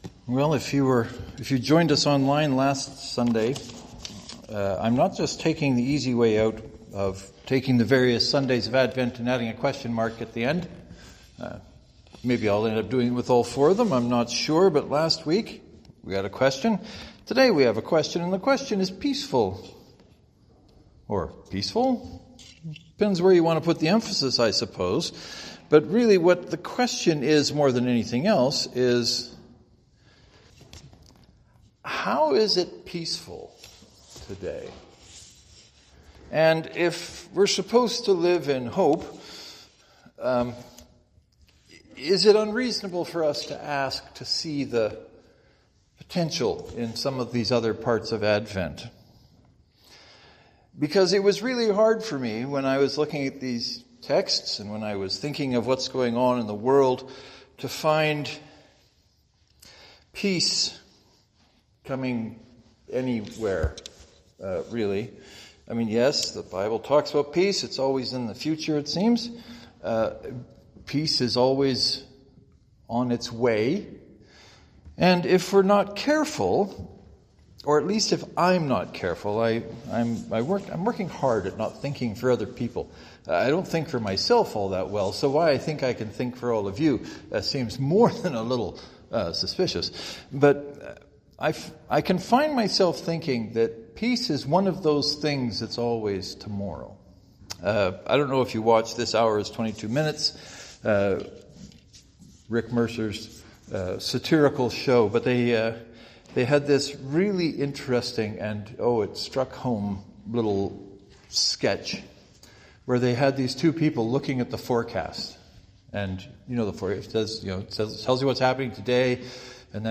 How can we be peaceful in a world that simply isn’t and refuses to try to be? Well, I preached a sermon about peace that you might find useful.